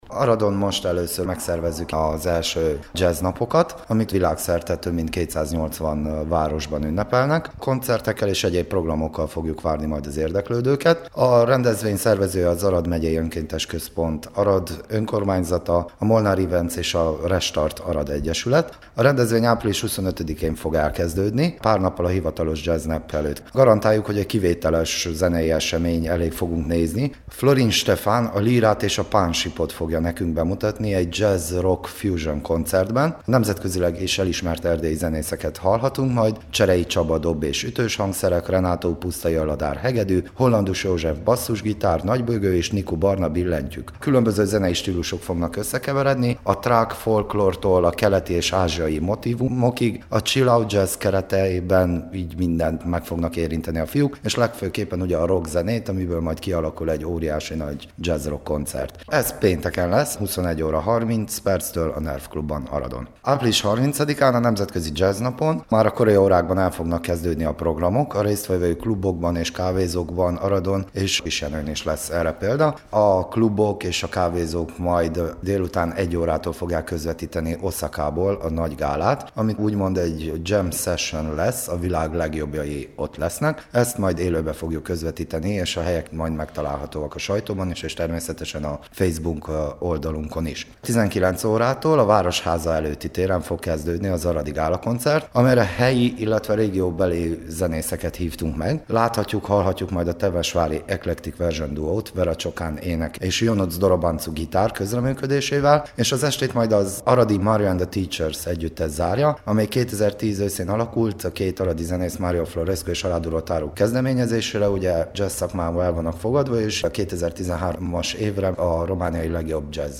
a Temesvári Rádió kulturális műsora számára